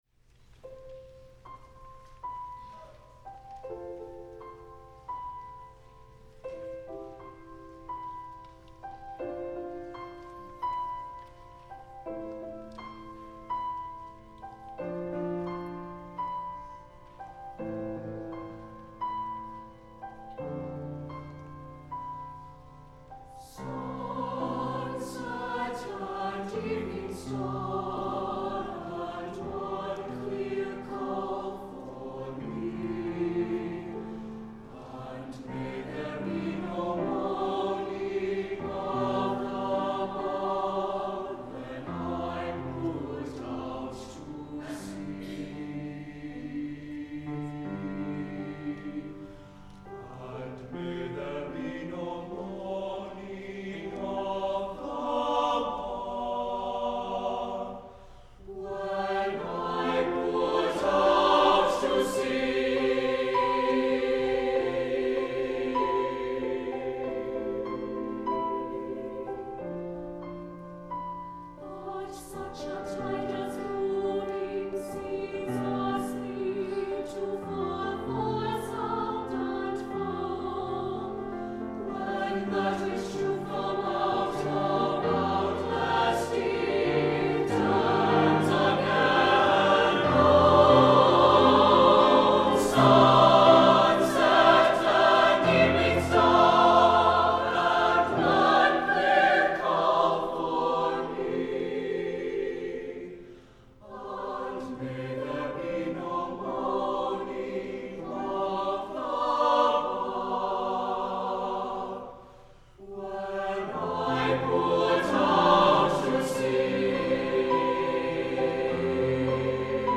SATB and piano